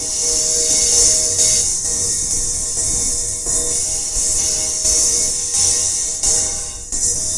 描述：只是我在麦克风在里面的时候，用手指绕着不锈钢碗旋转。然后切碎并有节奏。
Tag: 130bpm 循环 不锈钢